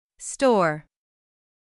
そして瞬間的に破裂させます。
/t/は息だけ、/d/は喉を震わせるのがポイントです。